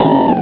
Cri de Barloche dans Pokémon Rubis et Saphir.